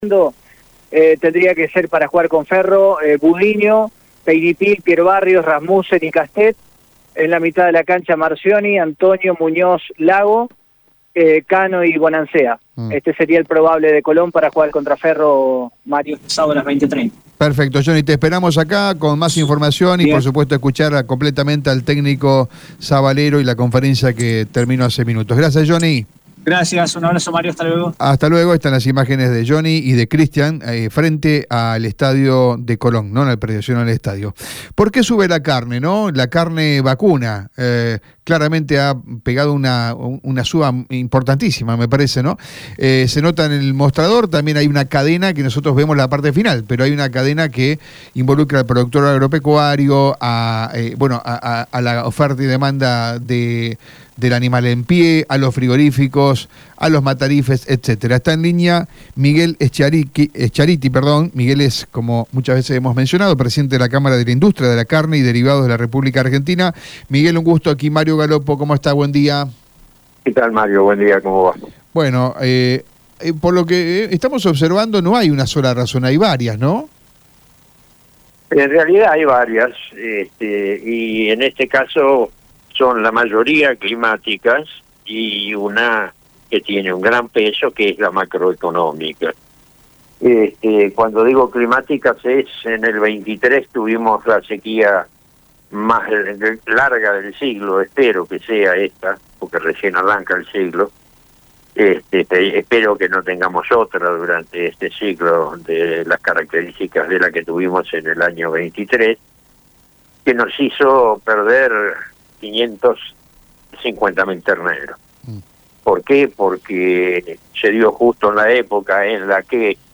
En diálogo radial